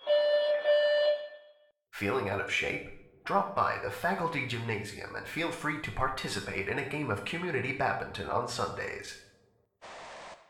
scpcb-godot/SFX/Room/Intro/PA/scripted/announcement5.ogg at 81ef11964c8ee75b67faf28b3237c393a288471c
announcement5.ogg